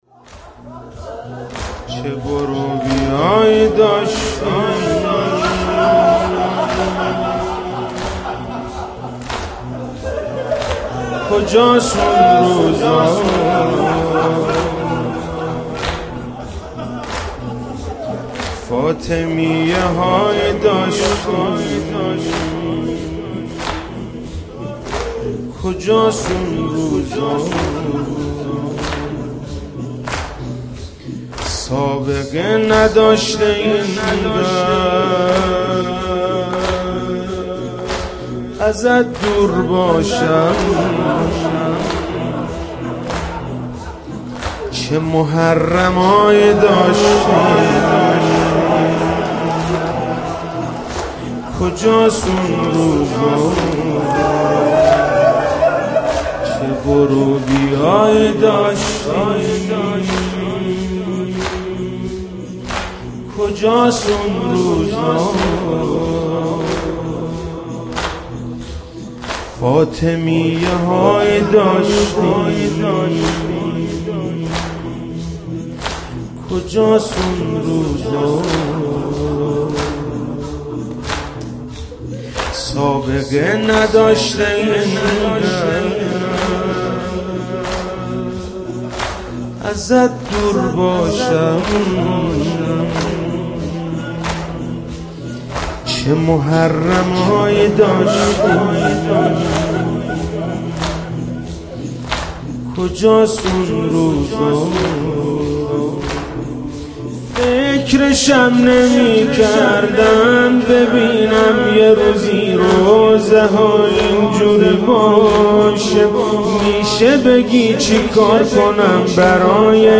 سینه زنی ایام فاطمیه.
مداح